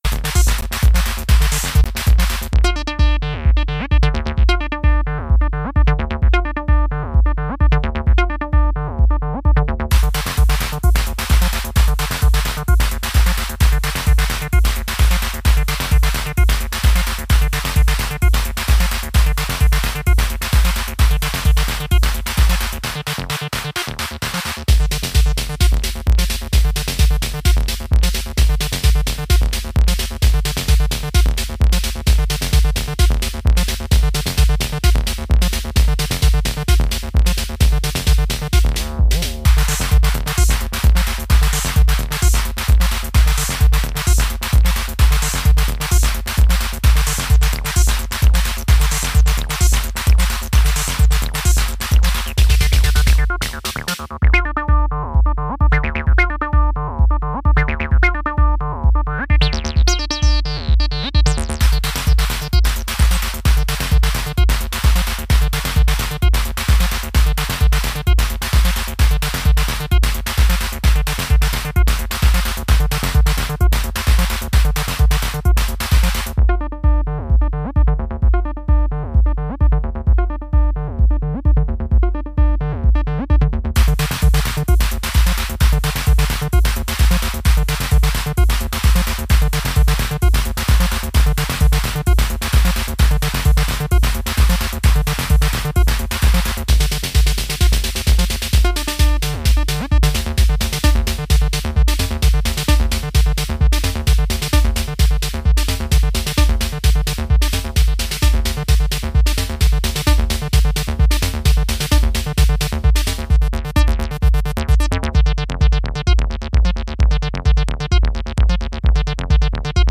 Classic Chicago jack tracks ...